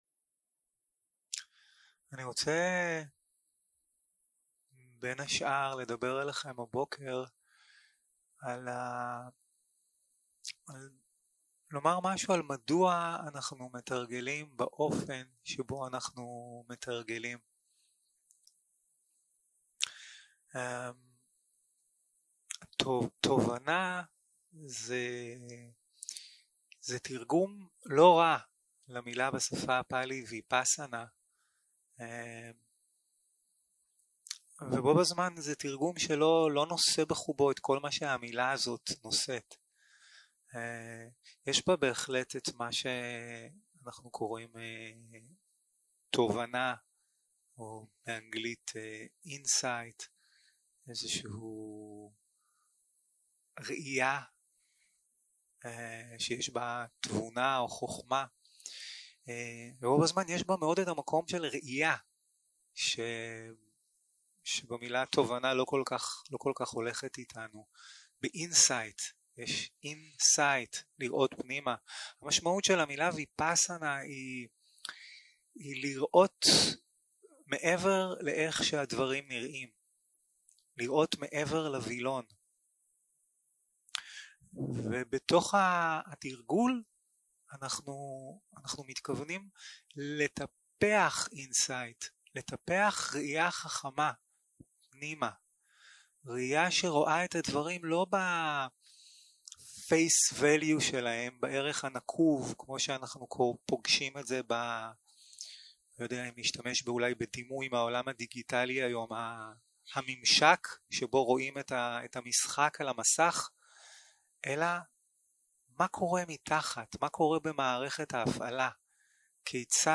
יום 3 - הקלטה 5 - בוקר - הנחיות למדיטציה